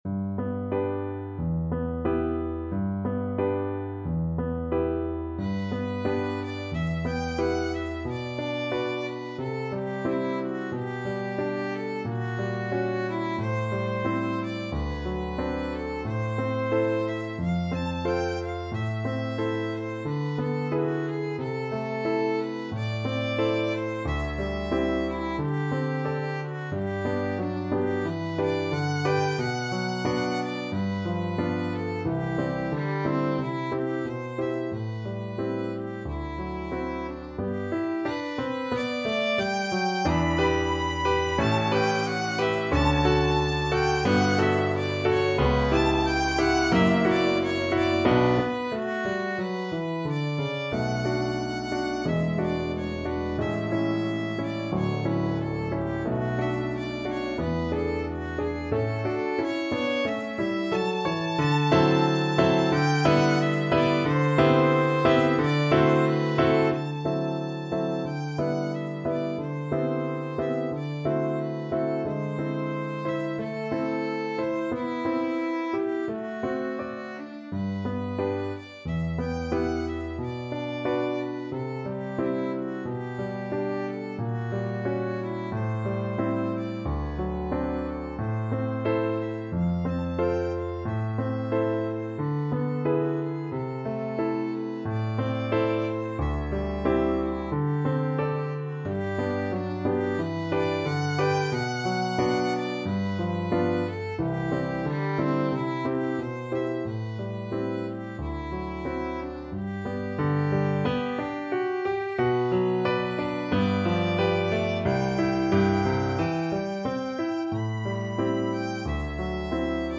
~ = 100 Allegretto con moto =90
2/4 (View more 2/4 Music)
Classical (View more Classical Violin Music)